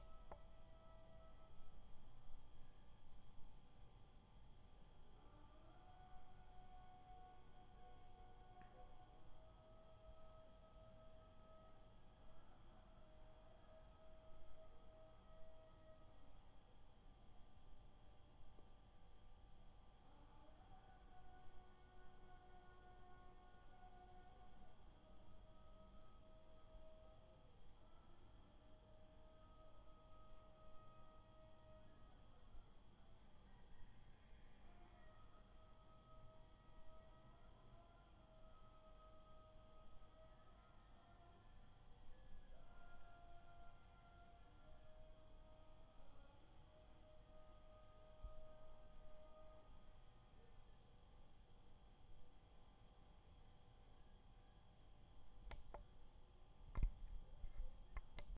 The next morning, I wake up at 4. Once again, the call to prayer reverberates warmly throughout my room.
Here’s audio I recorded of my daily wake up call:
call-to-prayer.m4a